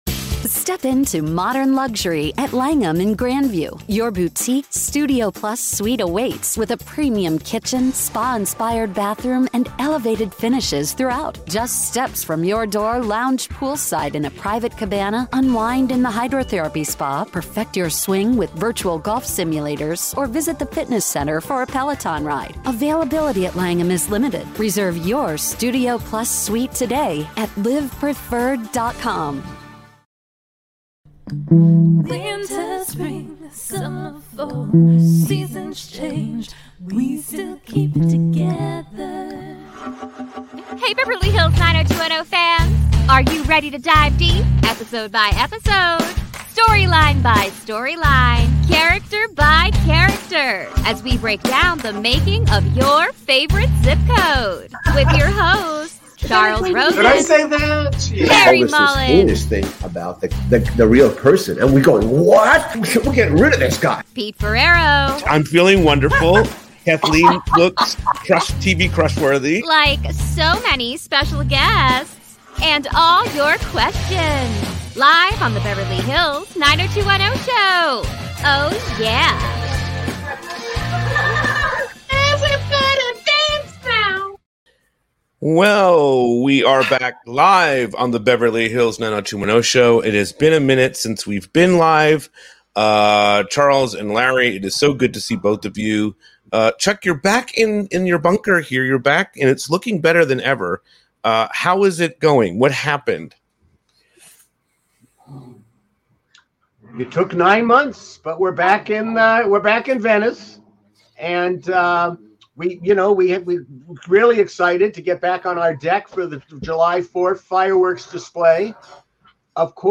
They will do dramatic readings as we find what is fact or fiction!
We are back LIVE!